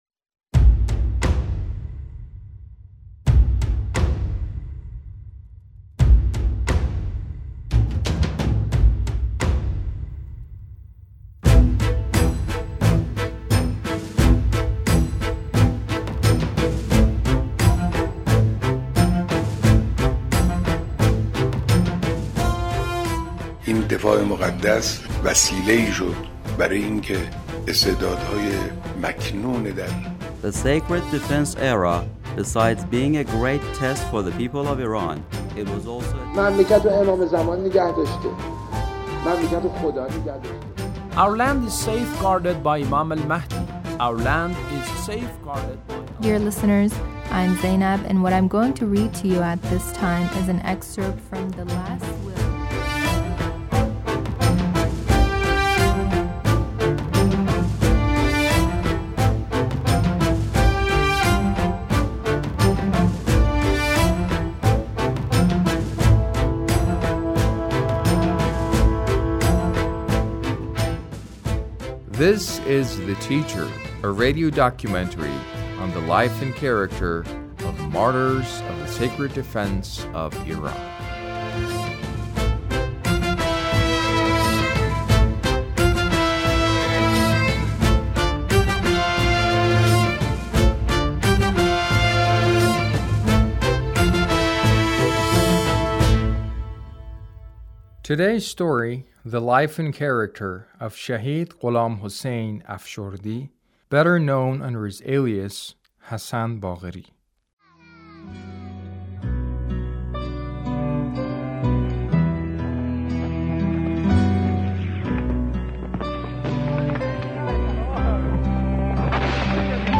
A radio documentary on the life of Shahid Hassan Baqeri - Part 2